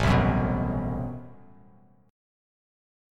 Am6add9 Chord
Listen to Am6add9 strummed